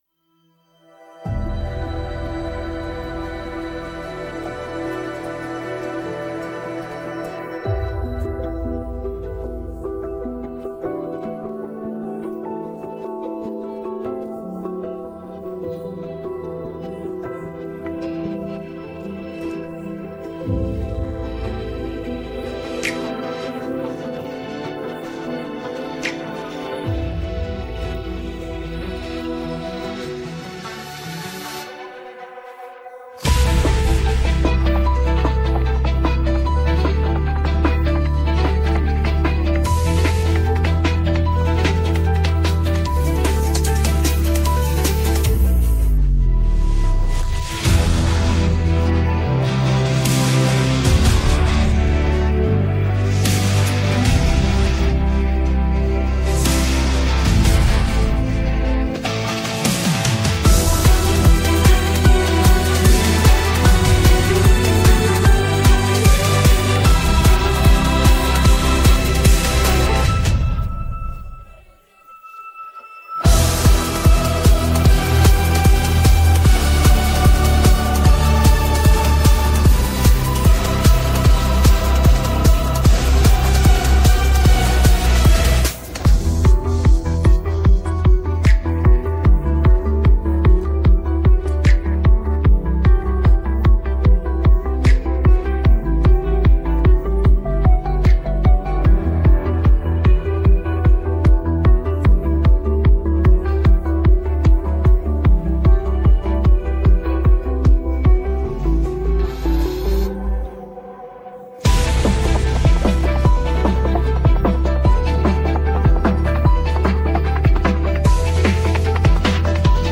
Лиричные песни о любви с элементами попа и шансона.